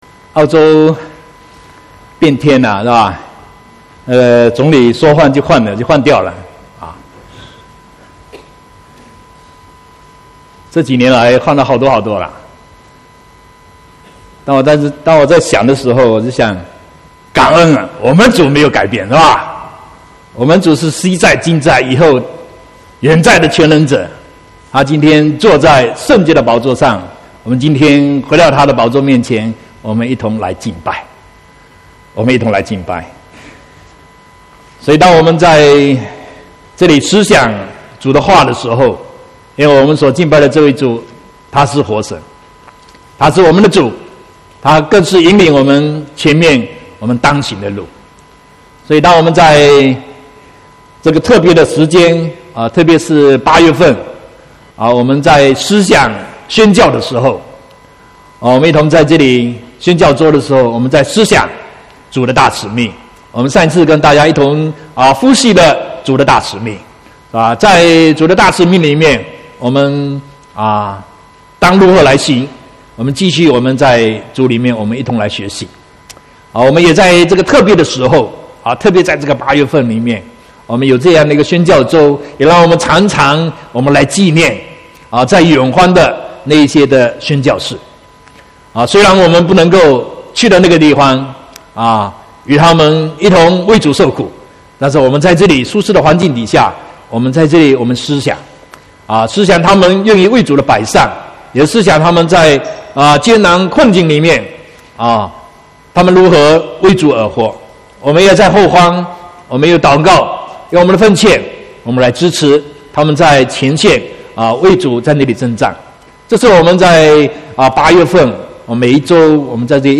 26/8/2018 國語堂講道